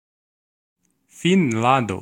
Ääntäminen
Ääntäminen Tuntematon aksentti: IPA: /ˈfɪnland/ Haettu sana löytyi näillä lähdekielillä: ruotsi Käännös Ääninäyte Substantiivit 1.